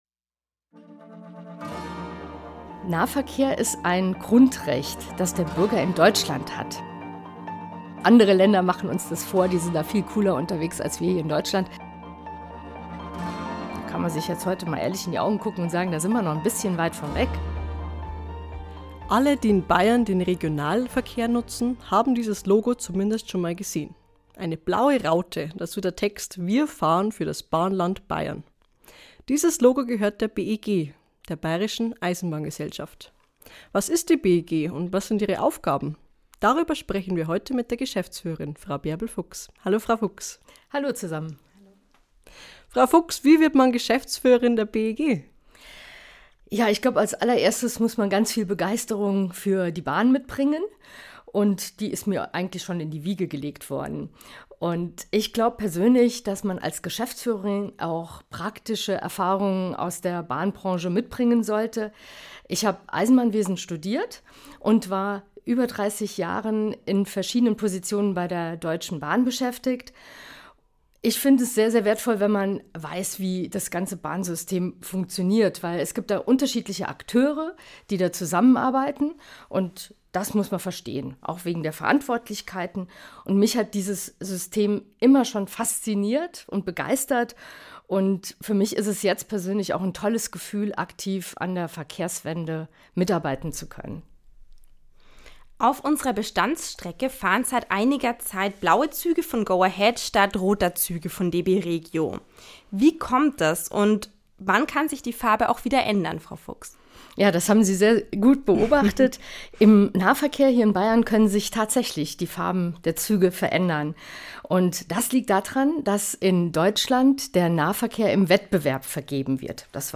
Dafür zuständig ist die Bayerische Eisenbahngesellschaft (BEG), die Strecken und Netze ausschreibt. Ein Gespräch